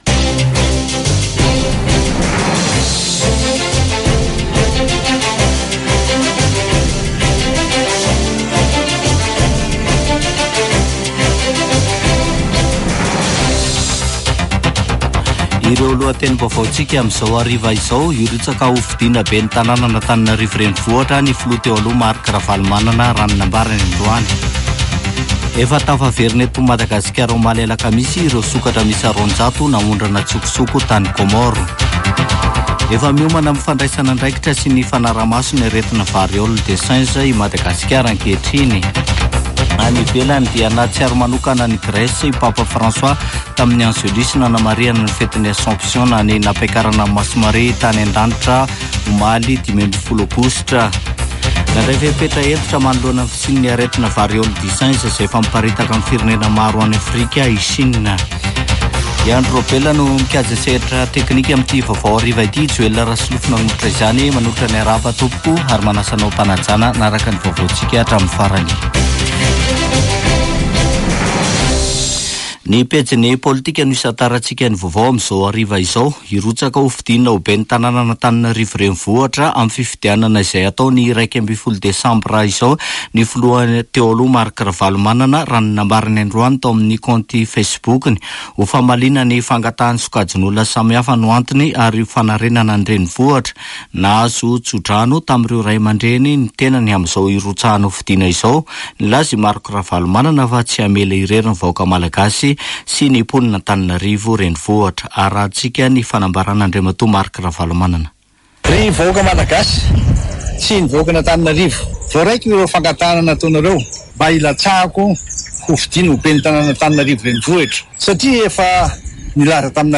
[Vaovao hariva] Zoma 16 aogositra 2024